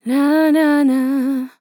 Nananah Sample
Categories: Vocals Tags: dry, english, female, fill, LOFI VIBES, Nananah, sample